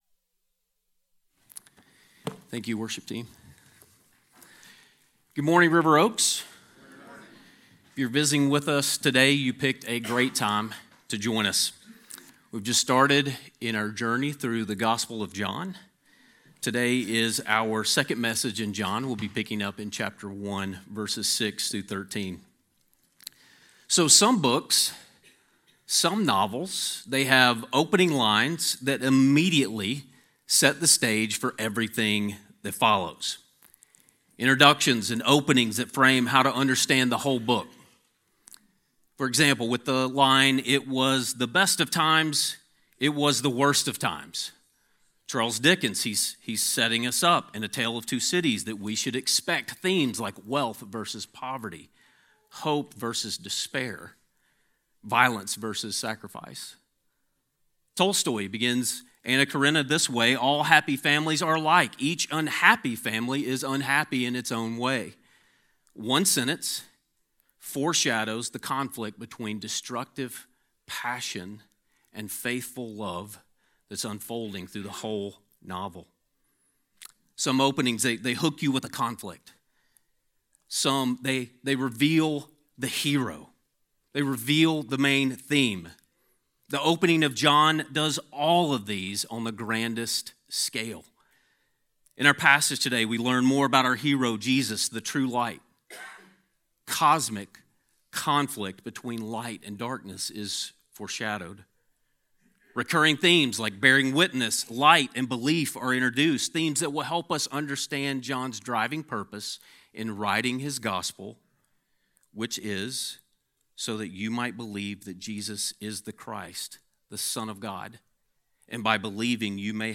A sermon on John 1:6-13